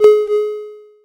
Ensuite, il te faudra un effet sonore.
signal.mp3